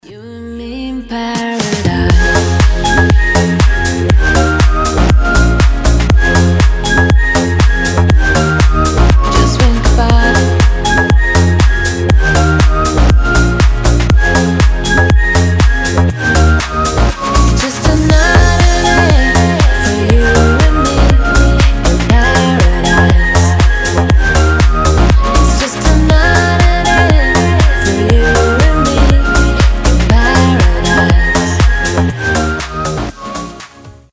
• Качество: 320, Stereo
свист
deep house
мелодичные
женский голос